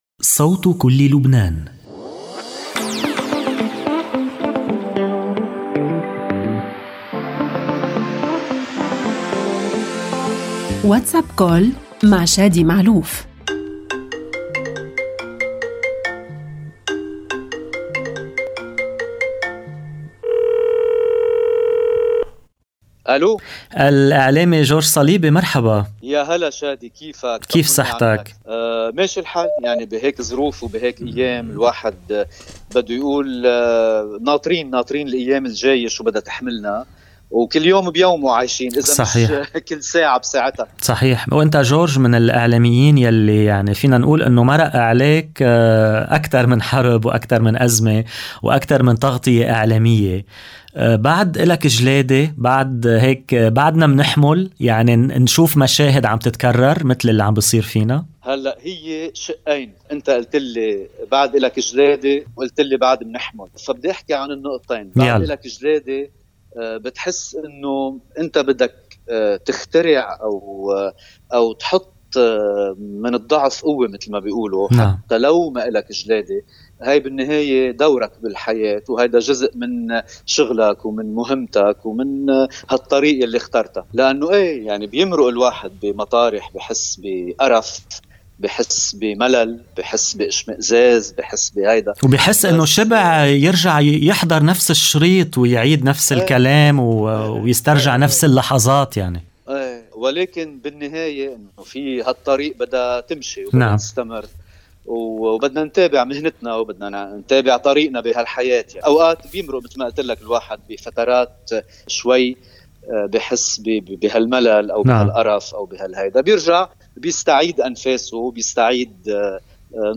WhatsApp Call الاعلامي جورج صليبي.. ما وصلنا سببه سوء قدر وسوء تقدير..